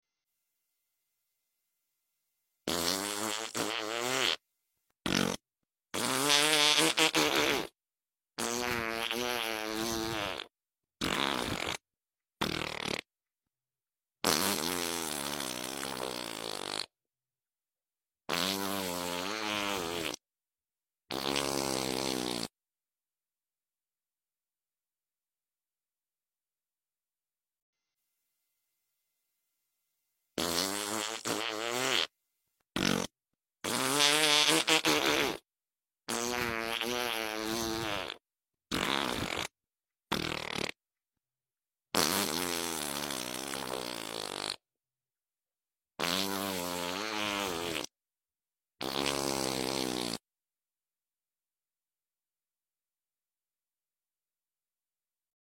Speaker Ke Bad Saaf Karne Sound Effects Free Download